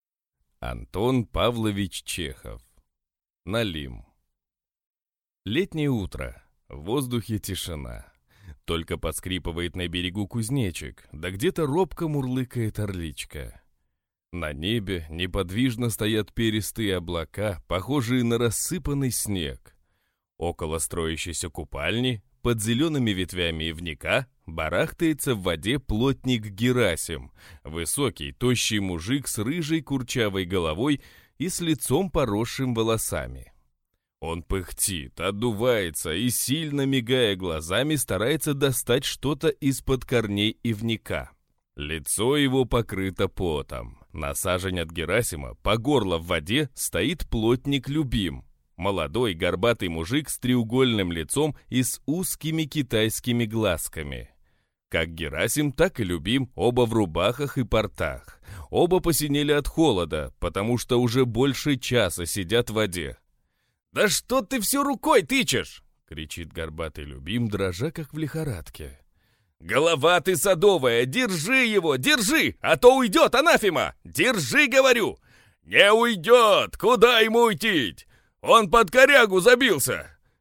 Аудиокнига Налим | Библиотека аудиокниг
Читает аудиокнигу